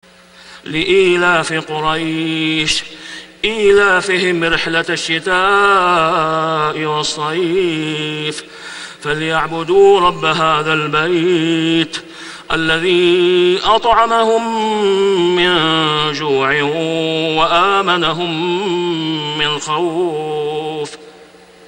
سورة قريش > السور المكتملة للشيخ أسامة خياط من الحرم المكي 🕋 > السور المكتملة 🕋 > المزيد - تلاوات الحرمين